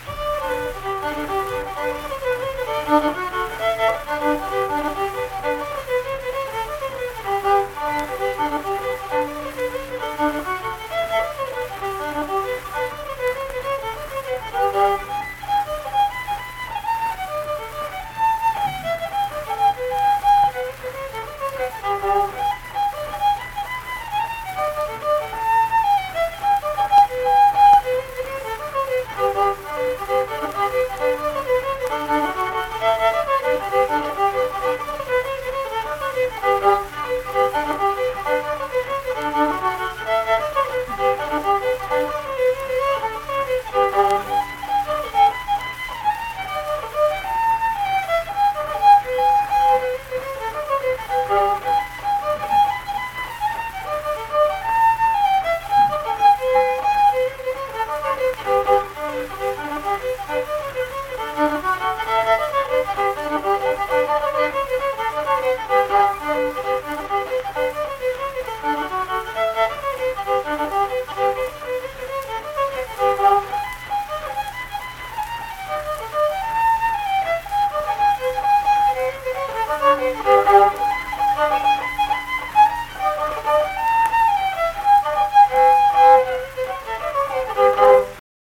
Unaccompanied fiddle music performance
Verse-refrain 4(2).
Instrumental Music
Fiddle